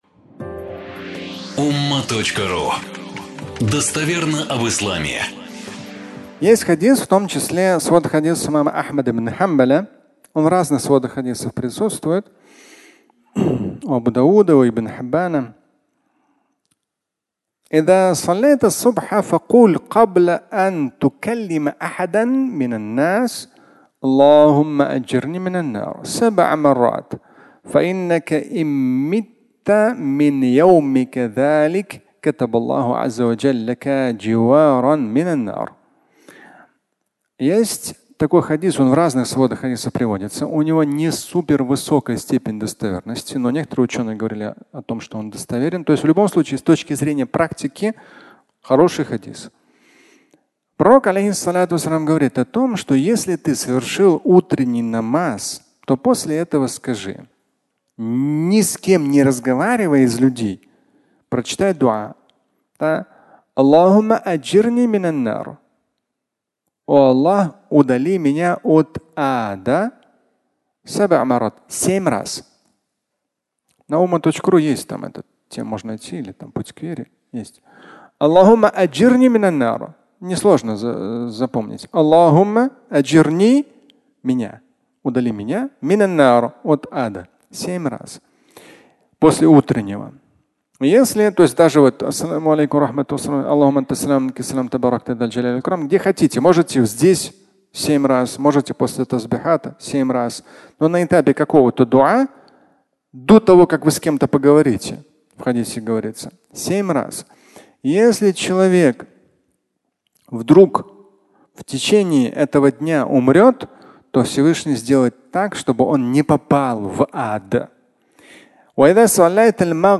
"Удали от Ада" (аудиолекция)